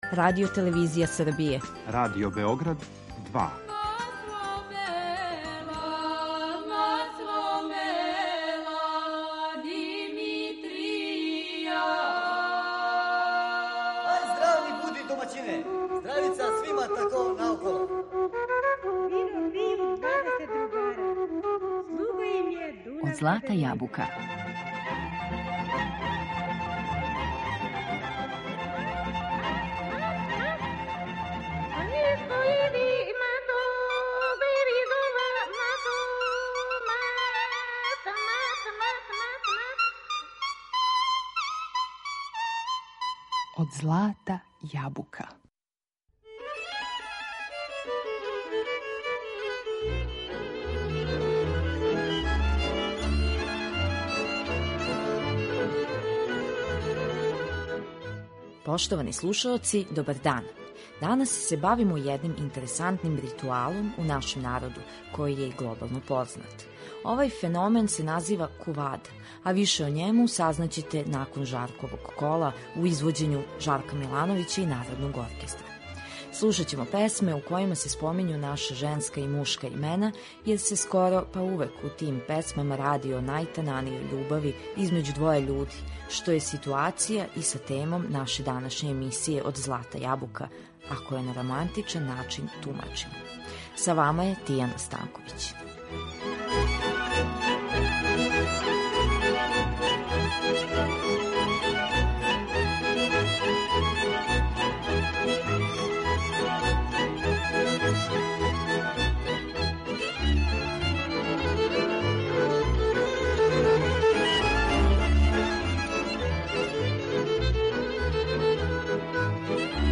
Овај феномен се назива кувада, ритуал познат под називом „мушке бабине". Слушаћемо песме у којима се спомињу наша женска и мушка имена јер je скоро увек у тим песмама реч о најтананијој љубави између двоје људи, што се може поистоветити са темом наше данашње емисије Од злата јабука , ако је на романтичан начин тумачимо.